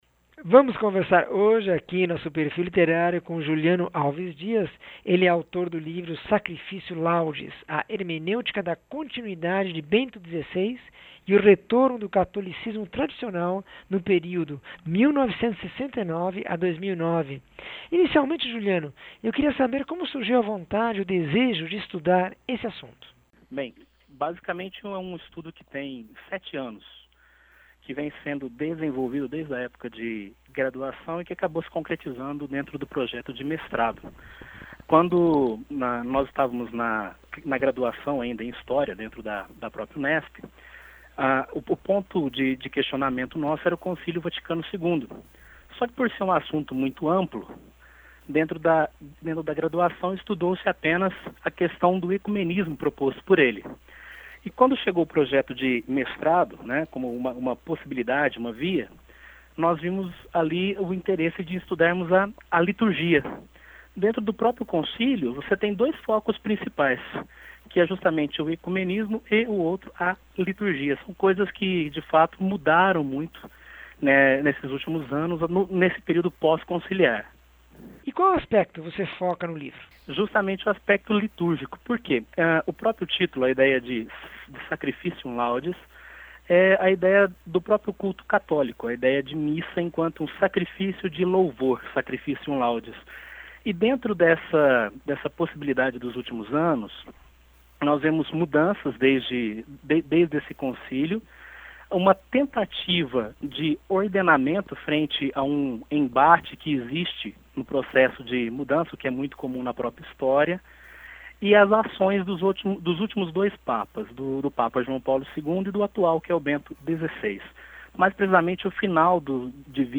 entrevista 1104